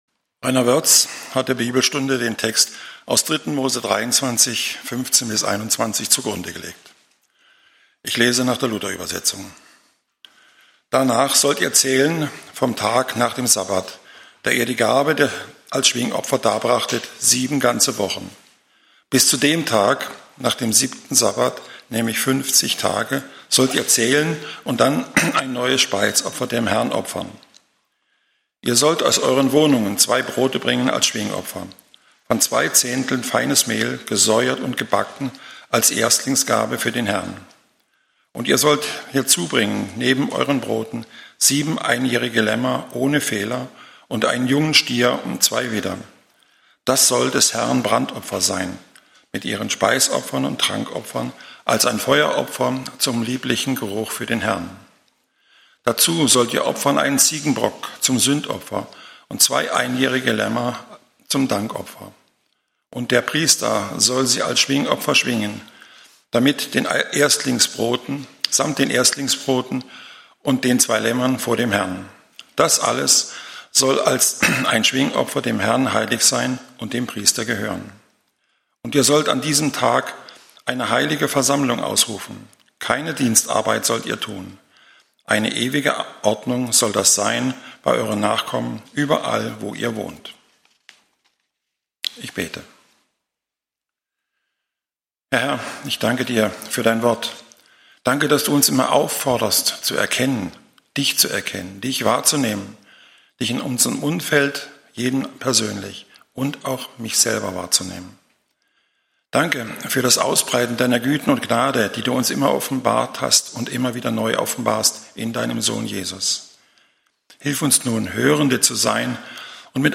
die kommenden Gerichte -Bibelstunde- Redner